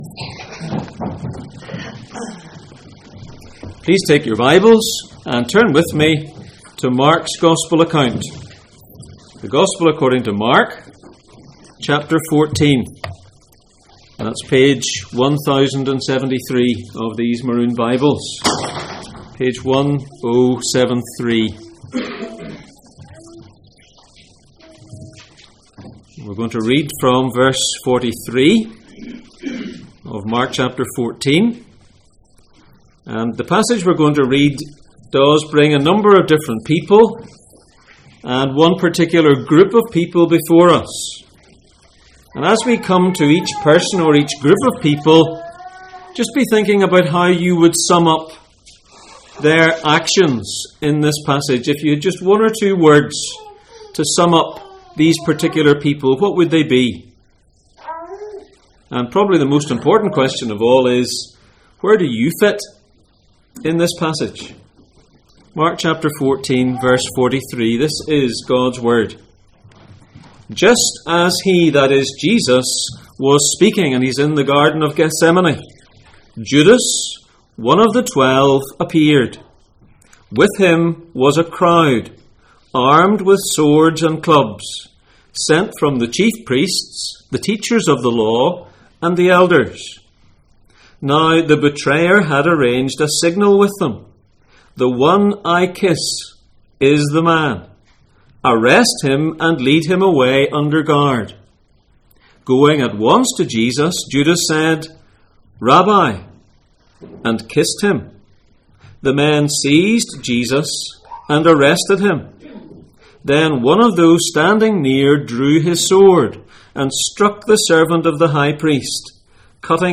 Jesus in Mark Passage: Mark 14:43-52, Psalm 109:1-31, Isaiah 53:12 Service Type: Sunday Morning